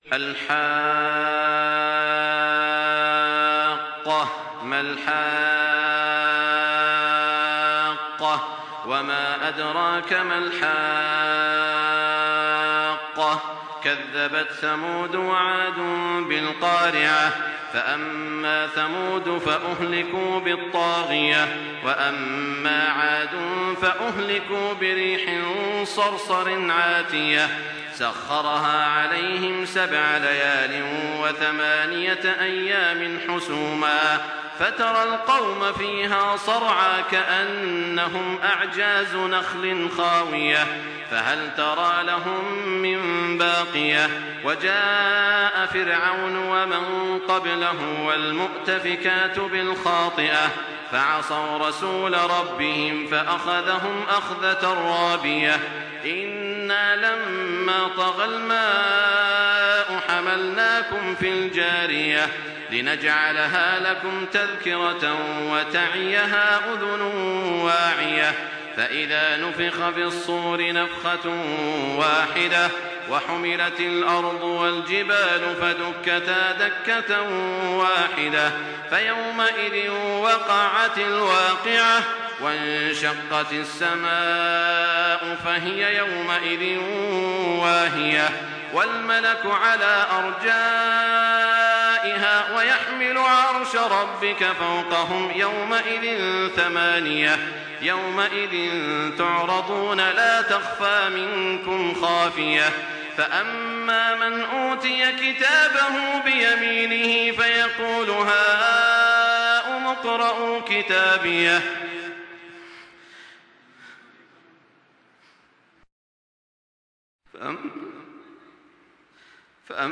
Surah আল-হাক্কাহ্ MP3 by Makkah Taraweeh 1424 in Hafs An Asim narration.